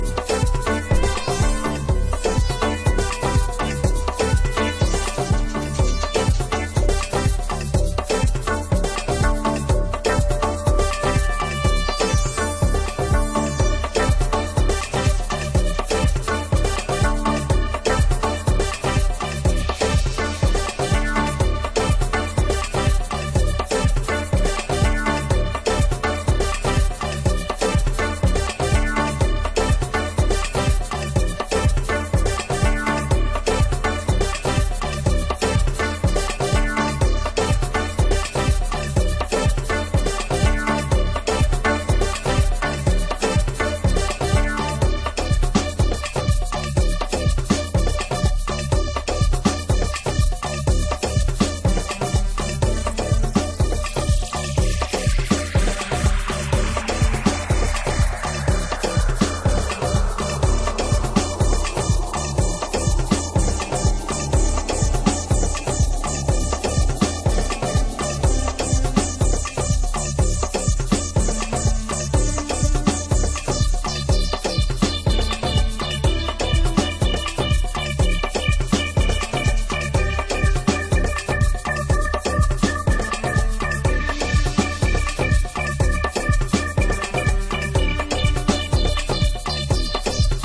Ethno-afro-flavored tribal grooves